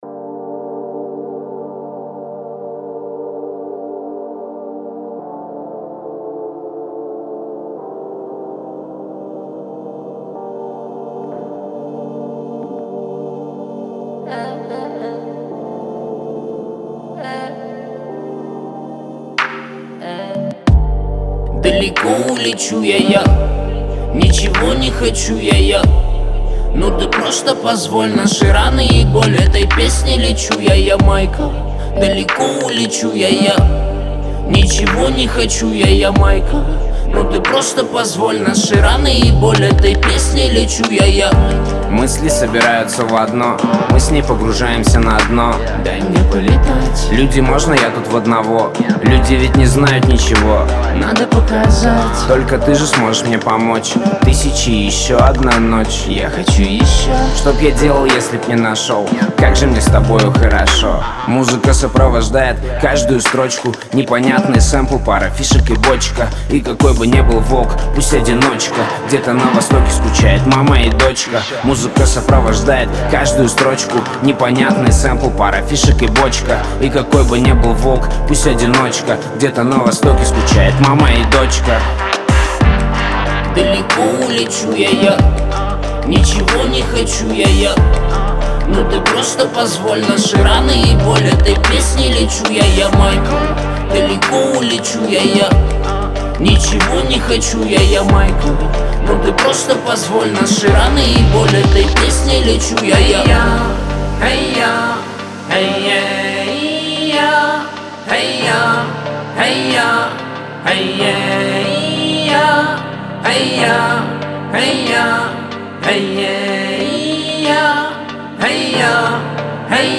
это яркий трек в жанре хип-хоп